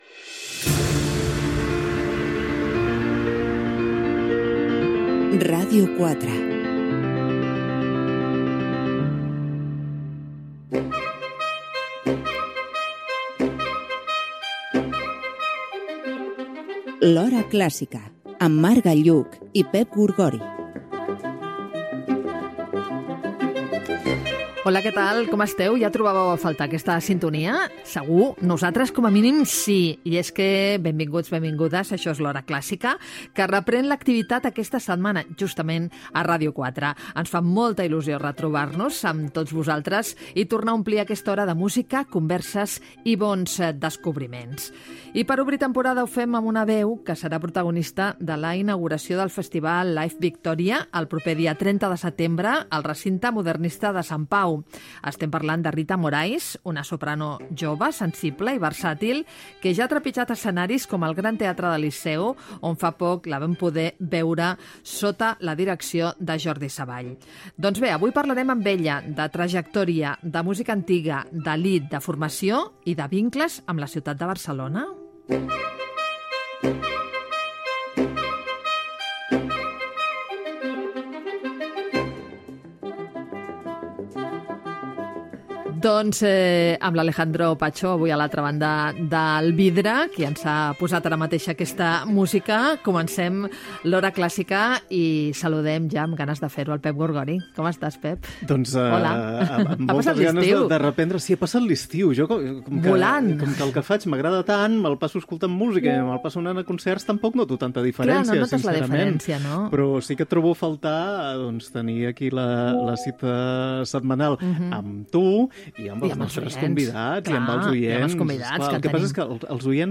Indicatiu de la ràdio, careta, presentació del primer programa de la temporada 2025-2026.
Musical